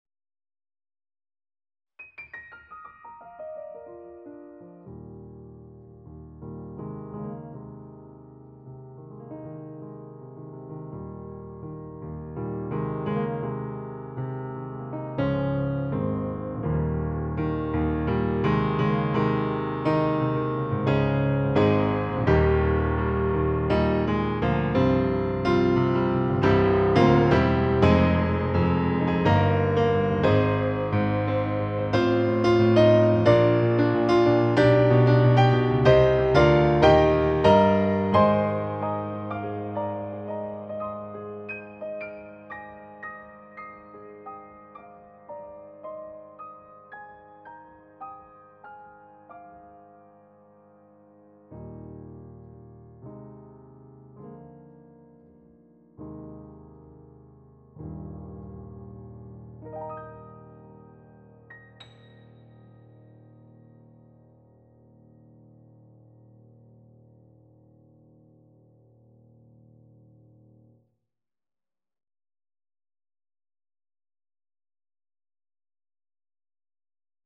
SK-EX Concert Grand Rich